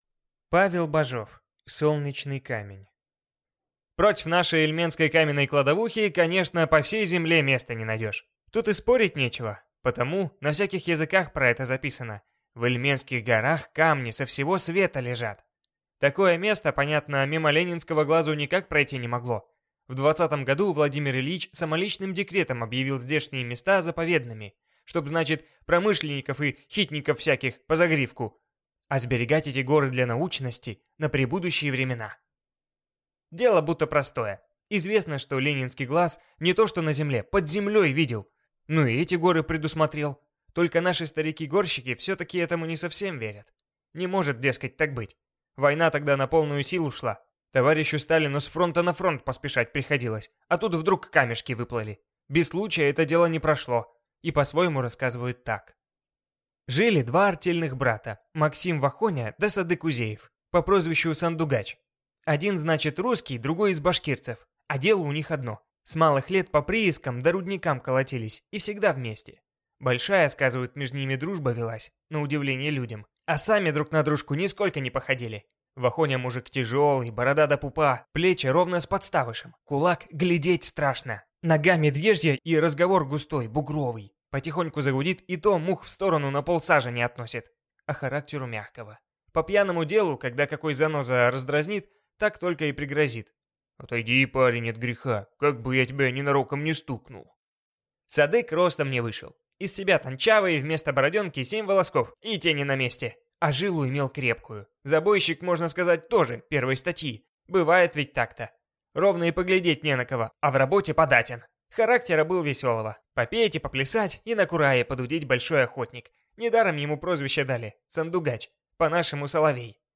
Аудиокнига Солнечный камень | Библиотека аудиокниг